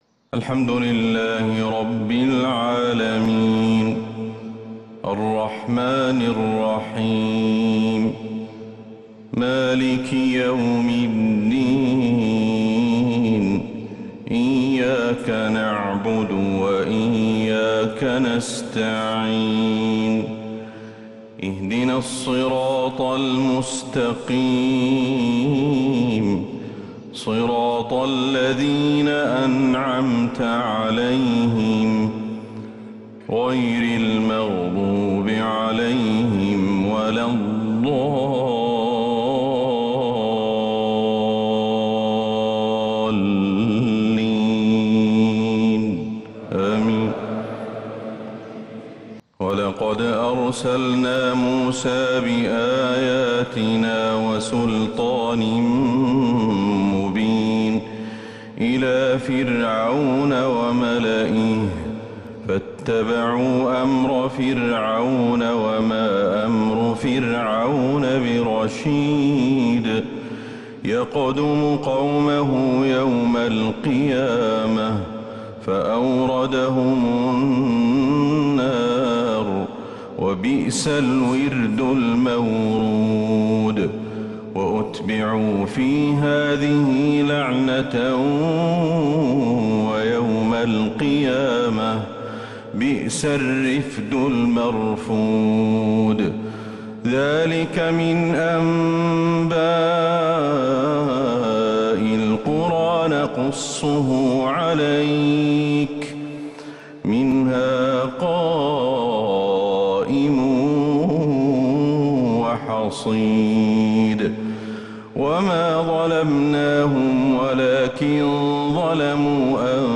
فجر الأحد 1 صفر 1444هـ آواخر سورة {هود} > 1444هـ > الفروض